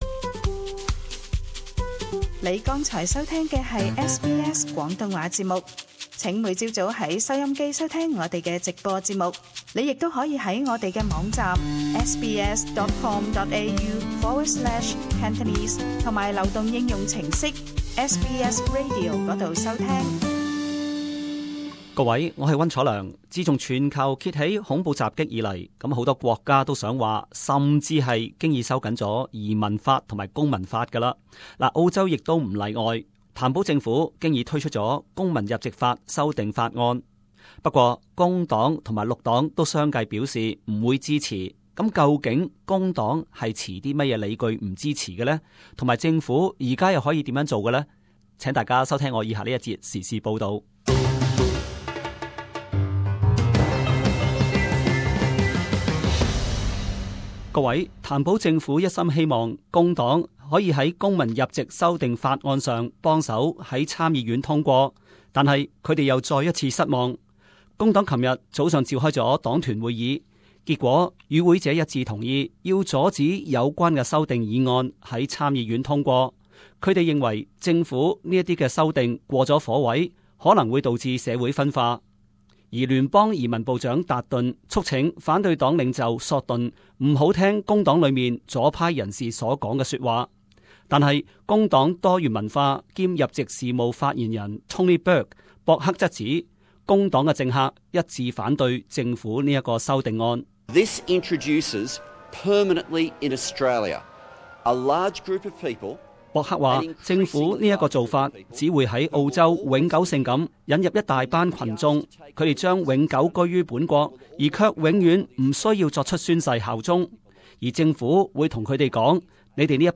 【時事報導】 工黨議員一致反對公民入籍修訂議案